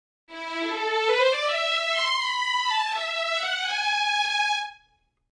Типа такого: в их примерчике медляк, в котором можно было бы и сусами оботись ) Вложения hs legato.mp3 hs legato.mp3 208 KB · Просмотры: 2.342